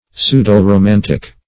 Search Result for " pseudo-romantic" : The Collaborative International Dictionary of English v.0.48: Pseudo-romantic \Pseu`do-ro*man"tic\, a. Pseudo- + romantic.] Falsely romantic.
pseudo-romantic.mp3